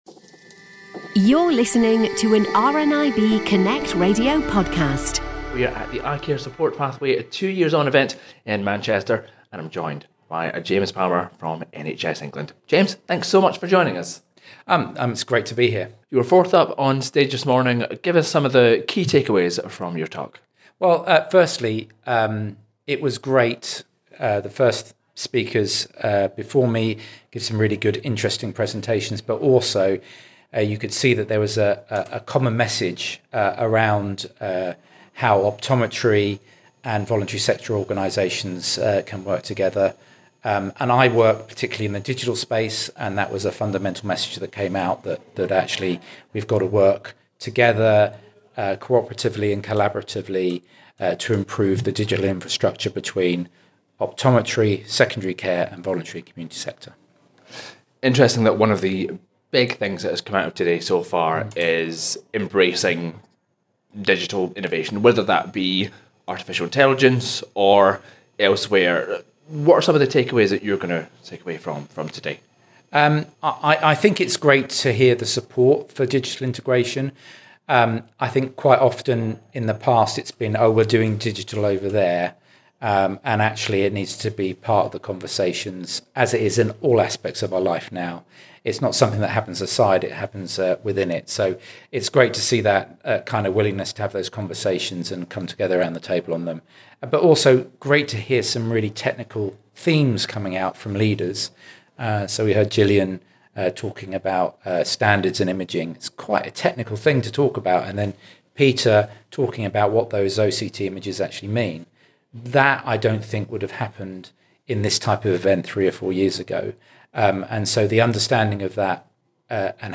Earlier this week, RNIB have marked two years since the launch of the Eye Care Support Pathway, with an event in Manchester which brought together optometrists, sector leaders and other partners.